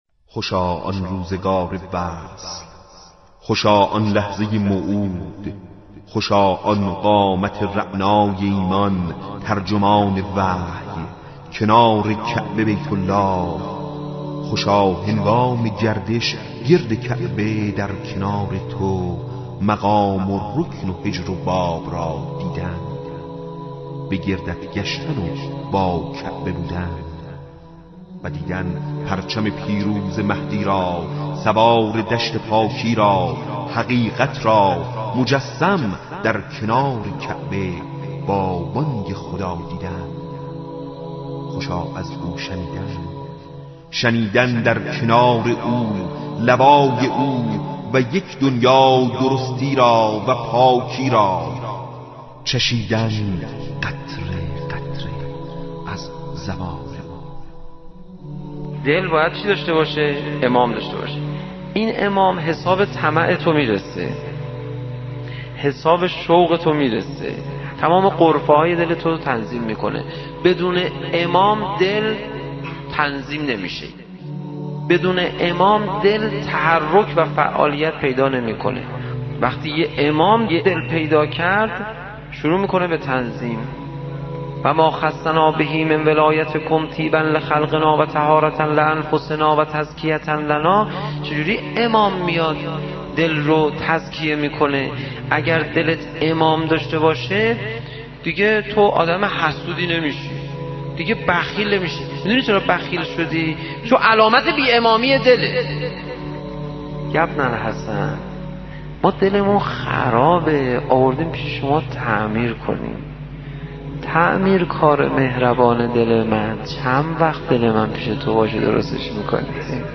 دکلمه‌های مهدوی